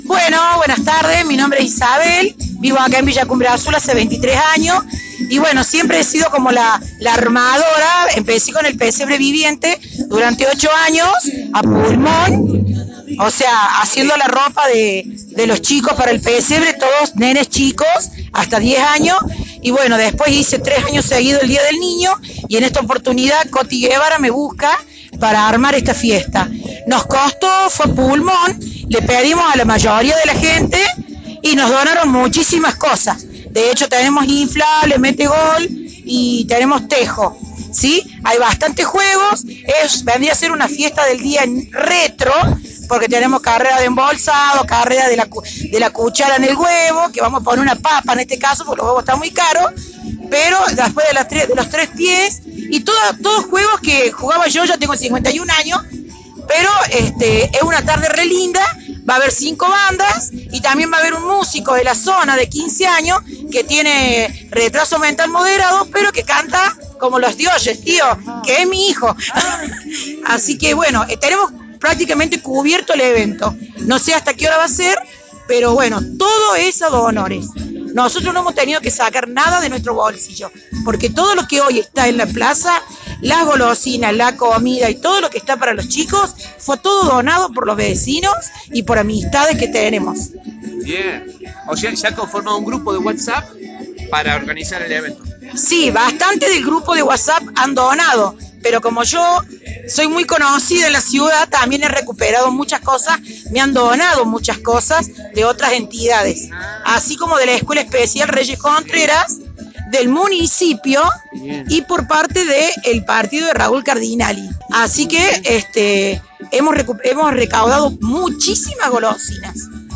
Radio del Monte estuvo presente en la fiesta que las vecinas y vecinos de Cumbre Azul organizaron para las niñas  y niños del barrio.